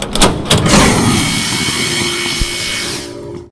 vent.ogg